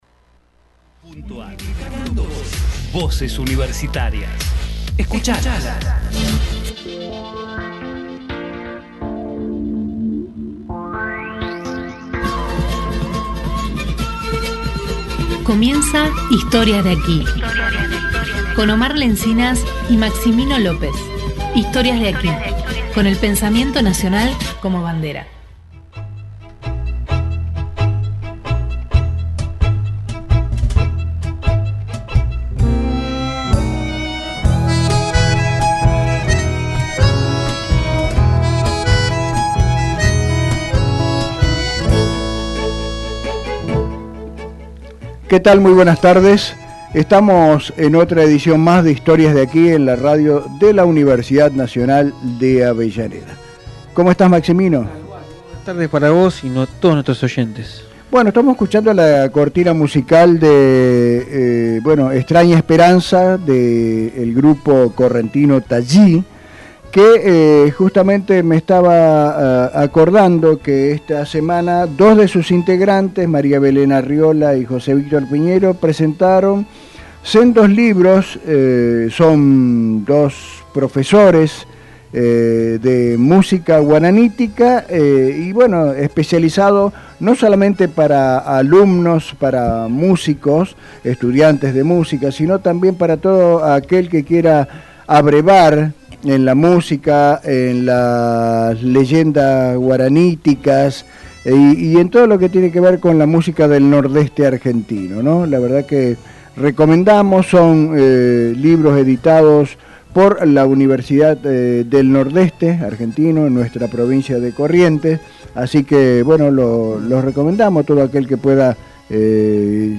Historias de aquí Texto de la nota: Historias de aquí Con el pensamiento nacional como bandera. Música regional, literatura y las historias que están presentes en la radio.